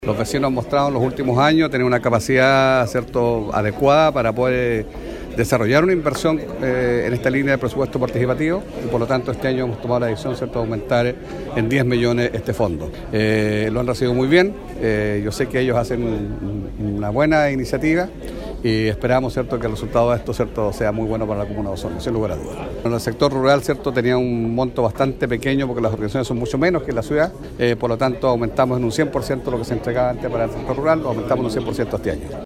CUÑA-ALCALDE-BERTIN-PRESUPUESTOS-1.mp3